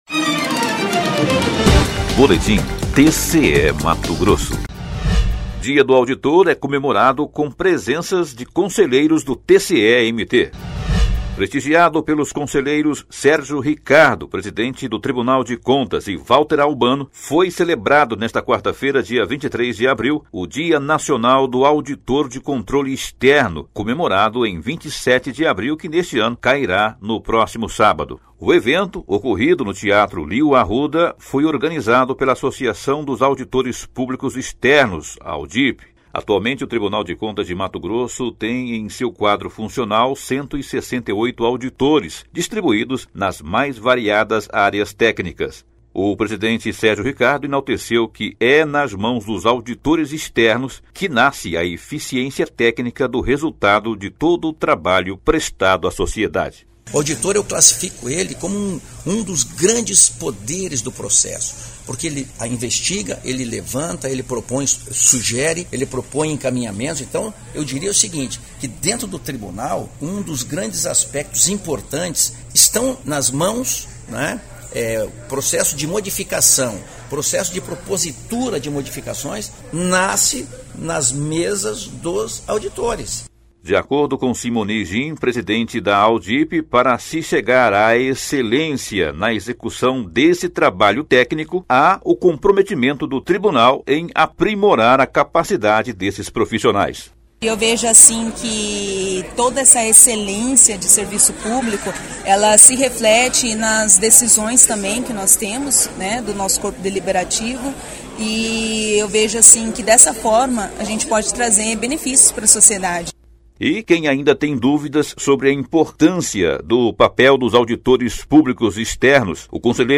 Sonora: Sérgio Ricardo – conselheiro-presidente do TCE-MT
Sonora: Valter Albano – conselheiro do TCE-MT